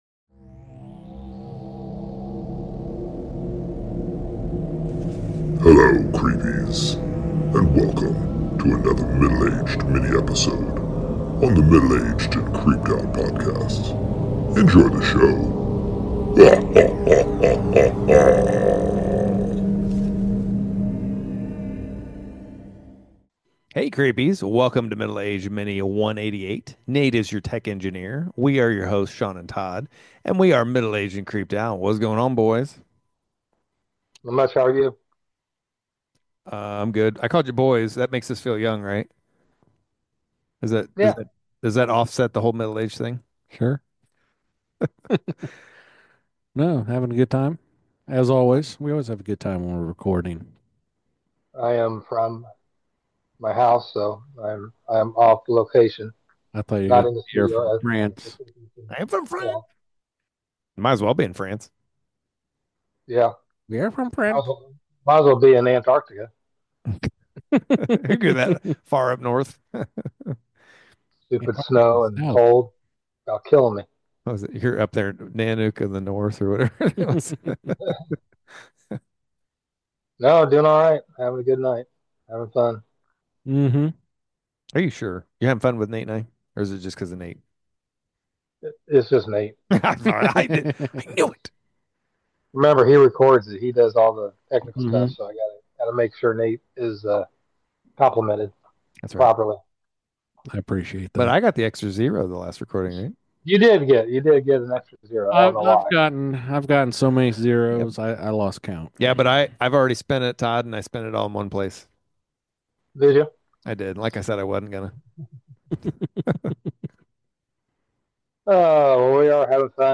The guys discuss the dark and storied history of a spooky Iowan city, complete with a ghost known as "The Joker"...Edinburgh Manor!!!